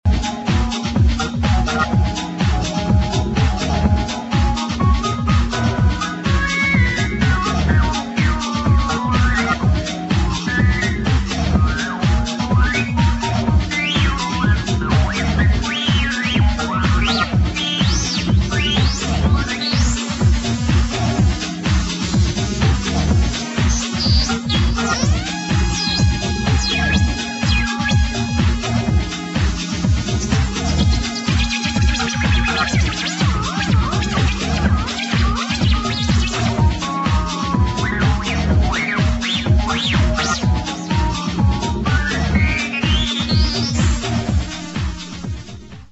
HOUSE | DISCO